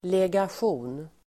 Ladda ner uttalet
Folkets service: legation legation substantiv, legation Uttal: [legasj'o:n] Böjningar: legationen, legationer Definition: diplomatisk representation mission substantiv, beskickning , legation , delegation , ambassad